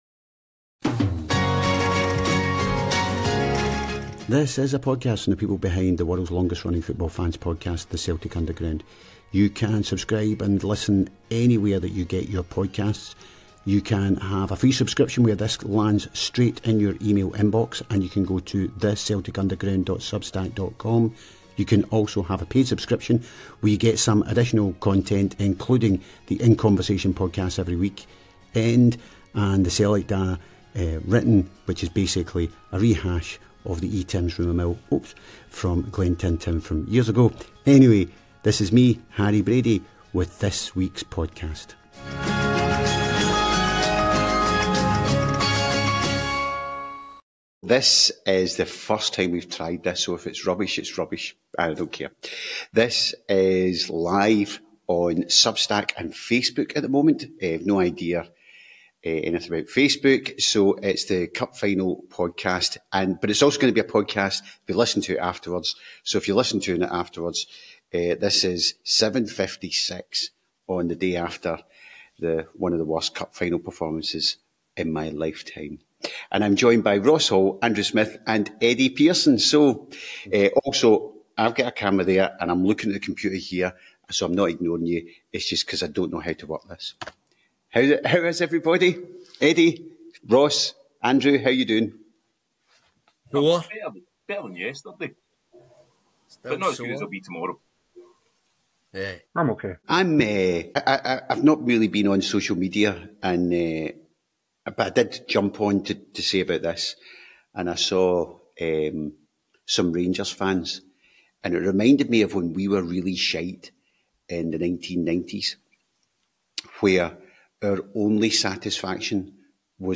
It was supposed to be our first live podcast on substack but it ended up being the first live podcast on Facebook and not live on Substack at all.